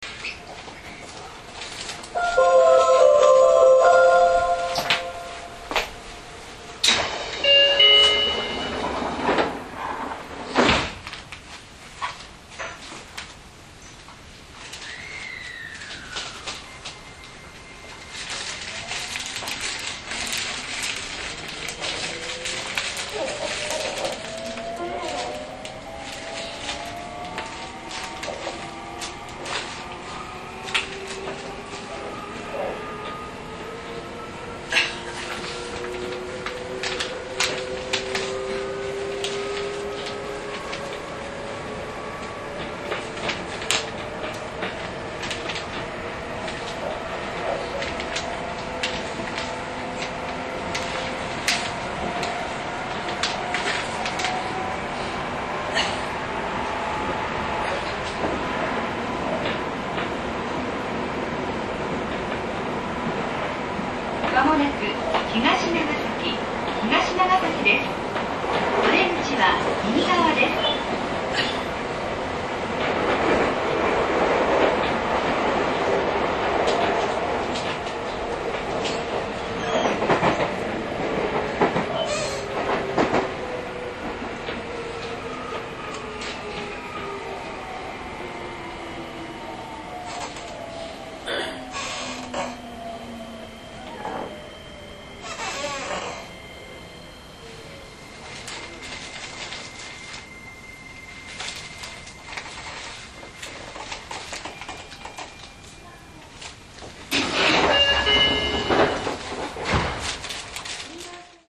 インバーター制御ですが、モーター音が静が過ぎです。
走行音